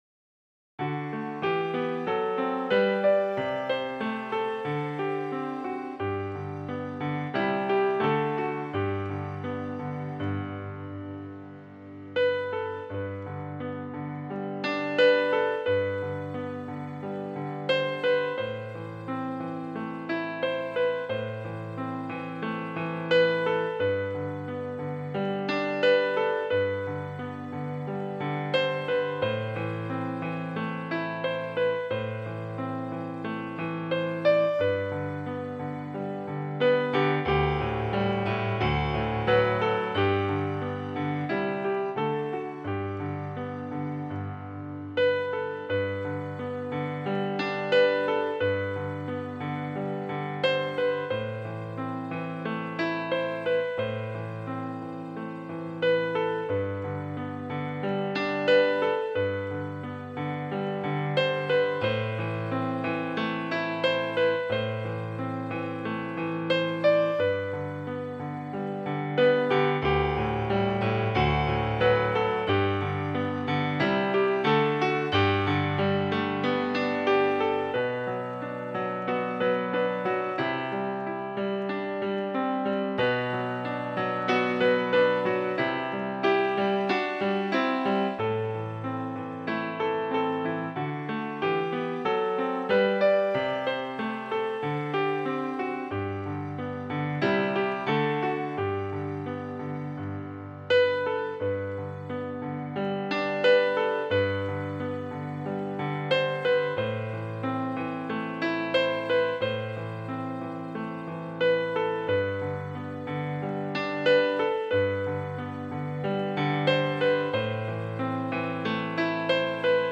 Original Piano / Keyboard